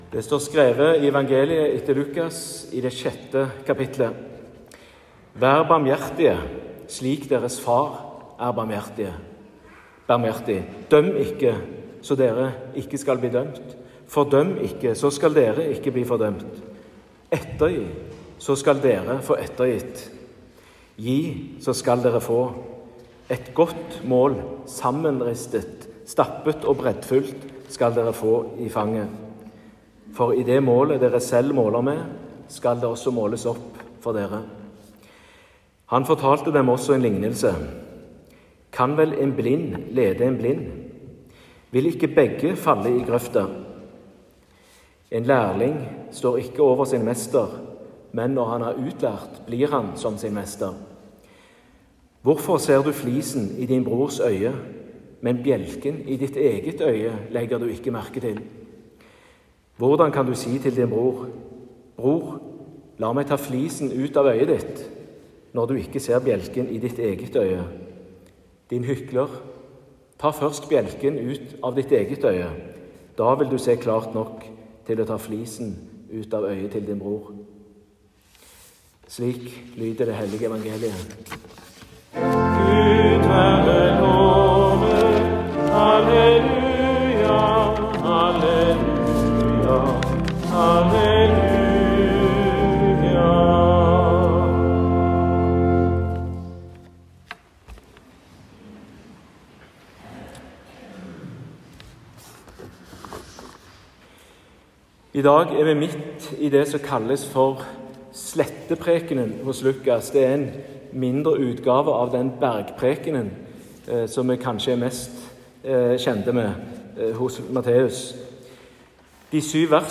Utdrag fra talen